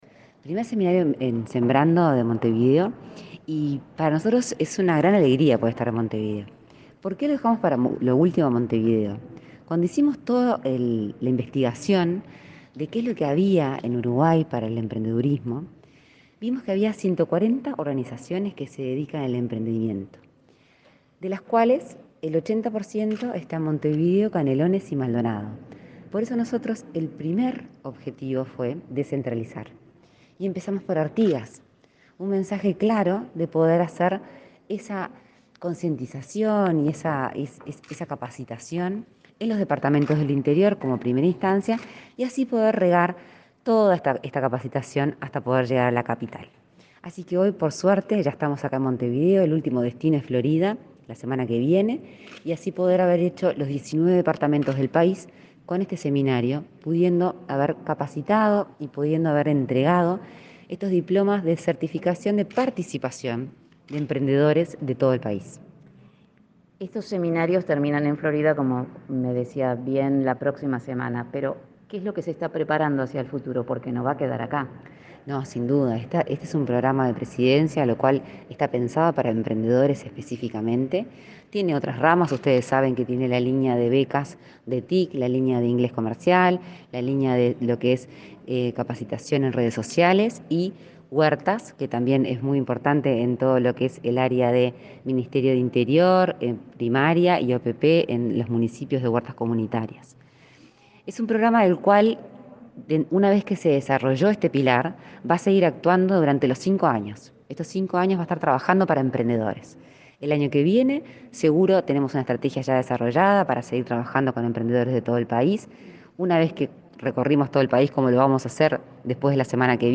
Entrevista a la impulsora del programa Sembrando, Lorena Ponce de León